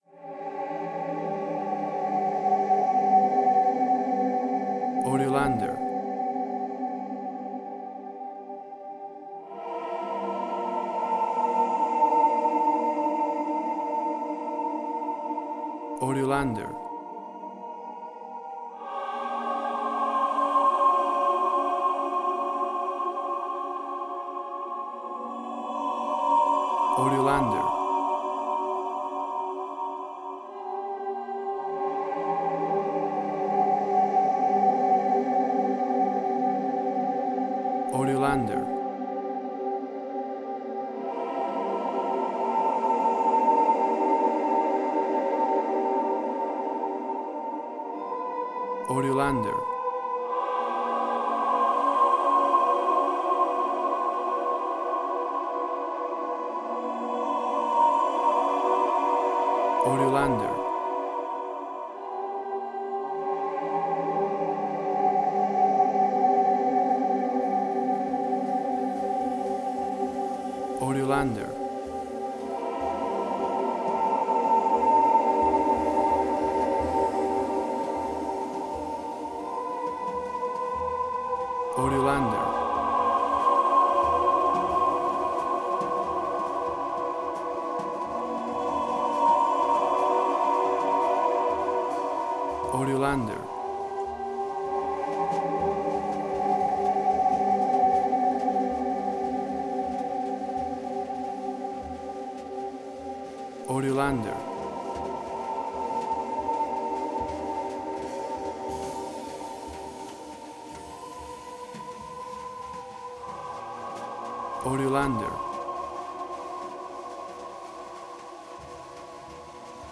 Modern Film Noir.